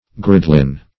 Search Result for " gridelin" : The Collaborative International Dictionary of English v.0.48: Gridelin \Grid"e*lin\ (gr[i^]d"[-e]*l[i^]n), n. [F. gris de lin gray of flax, flax gray.]
gridelin.mp3